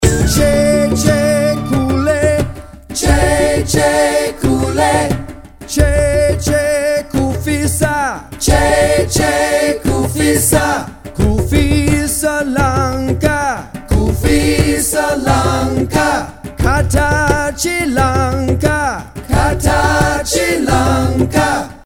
Traditional - Ghana
Song, Chant, Call & Respose, Circle Game
Chant and echo while dancing.